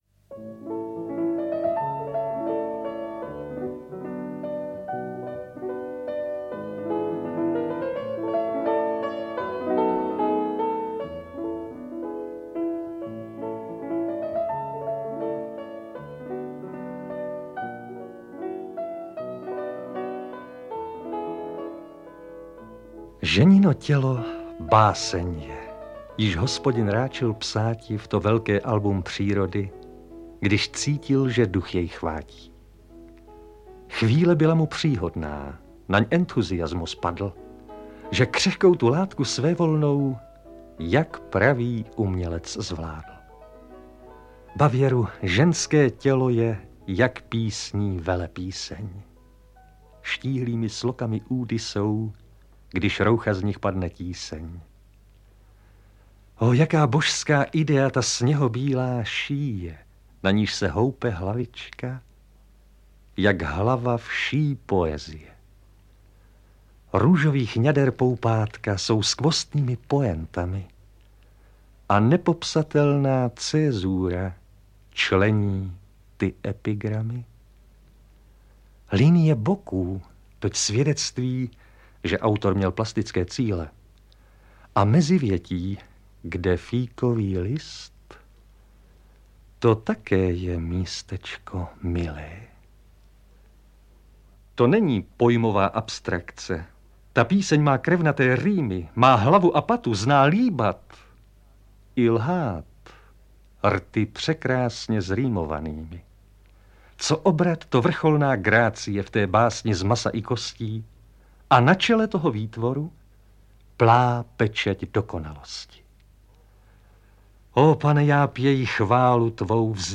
Píseň písní a další básně audiokniha
Ukázka z knihy